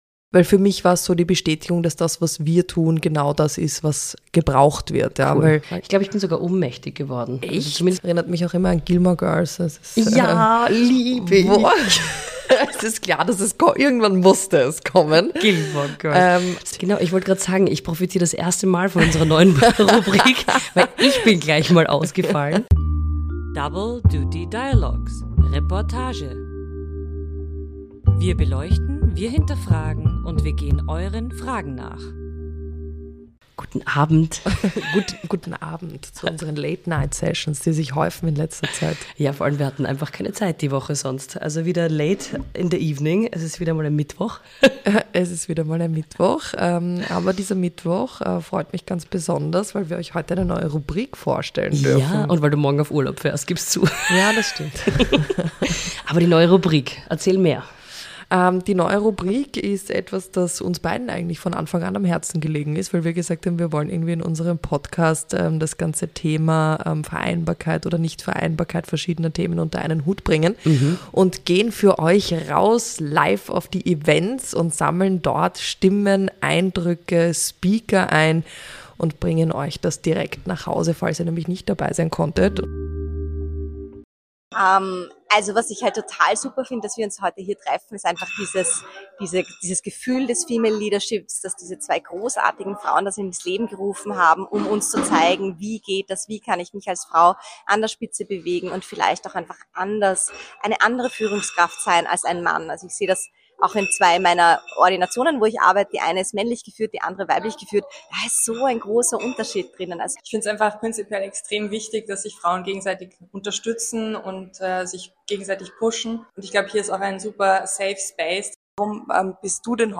In dieser Podcast-Folge: Start unserer neuen Rubrik: Reportage! Diesmal kommen nicht nur wir zu Wort, sondern auch spannende Frauen, die ihre Erfahrungen und Visionen teilen.
Bleibt dran für spannende O-Töne und inspirierende Geschichten direkt von der Erfolgsschwestern-Konferenz!